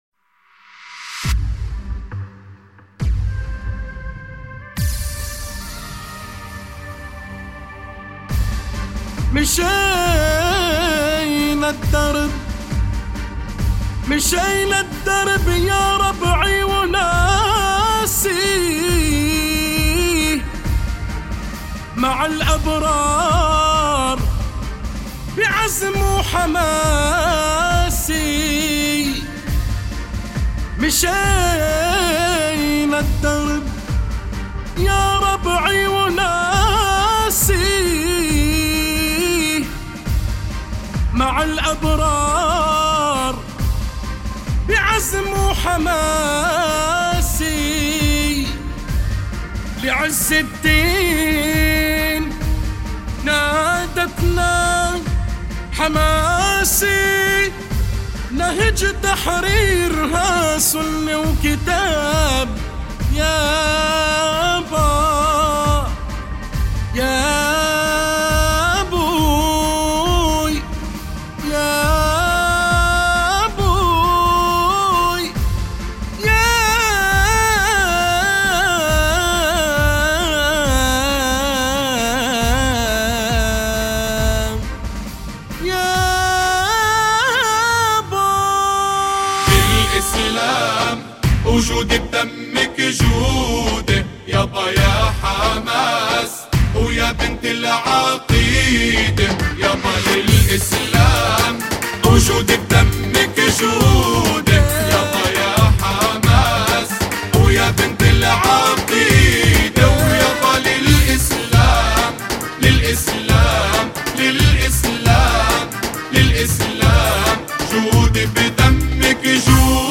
أناشيد فلسطينية... للإسلام جودي